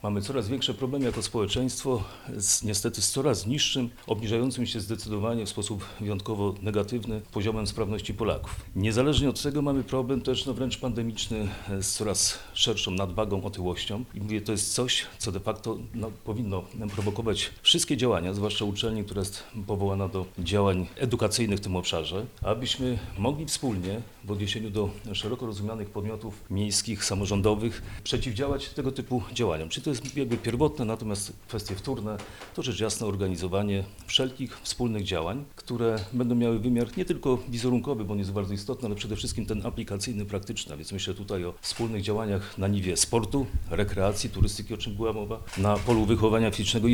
Powodem, dla którego Urząd Marszałkowski i AWF połączyli siły jest ogólnie pogarszający się stan obniżającej się sprawności fizycznej wśród Polaków, a także coraz częstsze problemy z otyłością. Co podkreśla rektor AWF – Tadeusz Stefaniak.